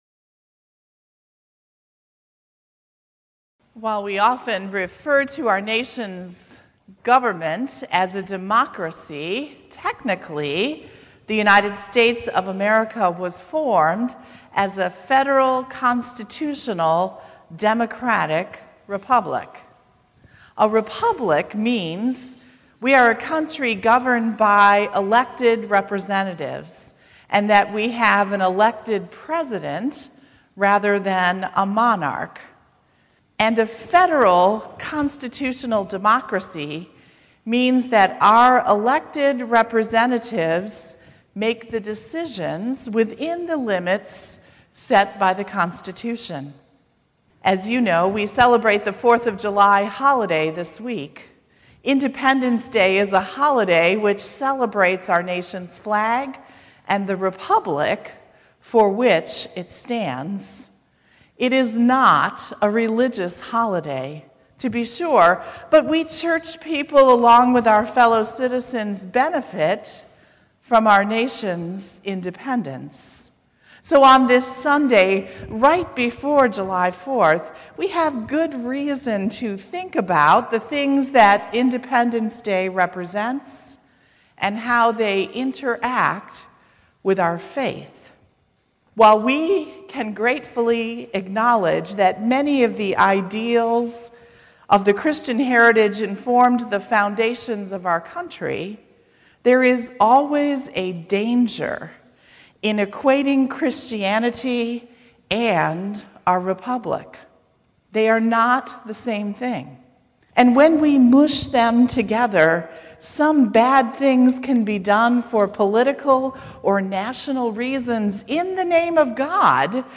July0118-Sermon.mp3